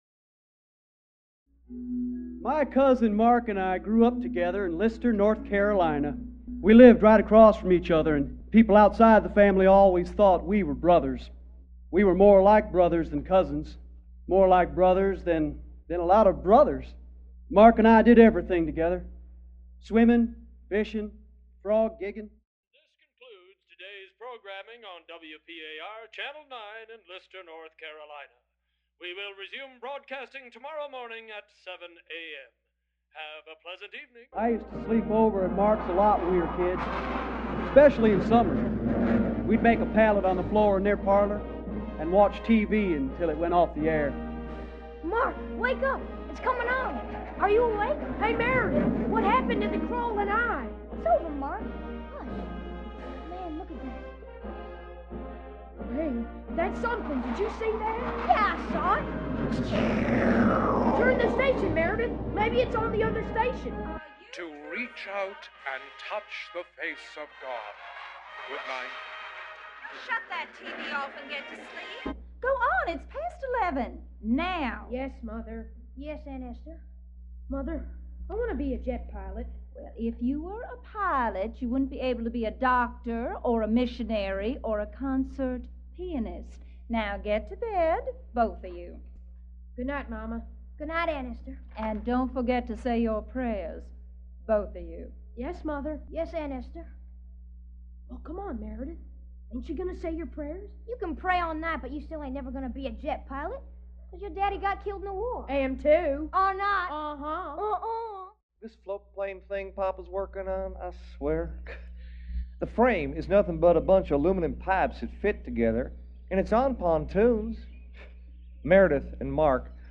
A Radio Play adapted from the novel The Floatplane Notebooks by Clyde Edgerton
First performed December 4, 1995 at the Steppenwolf Upstairs Theatre